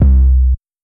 808 (All Mine).wav